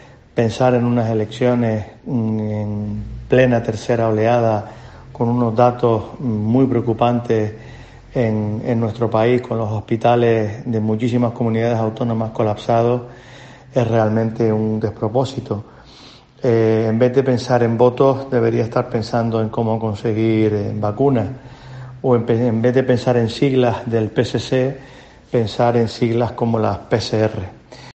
Habla Miguel Ángel Ponce, diputado regional del PP en Canarias